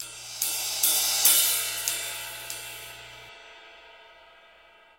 小型打击乐器 " smallsizz1
描述：打击乐，钟形钹，咝咝声
Tag: 打击乐器